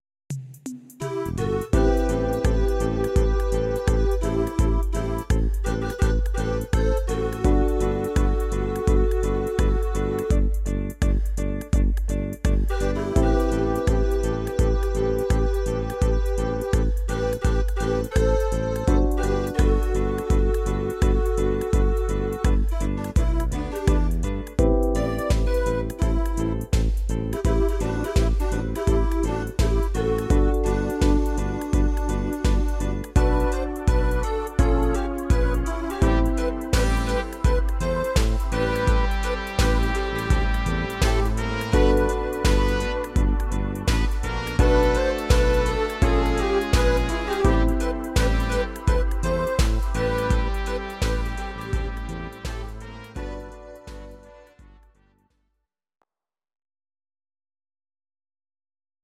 Audio Recordings based on Midi-files
Pop, Rock, 2000s